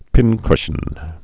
(pĭnkshən)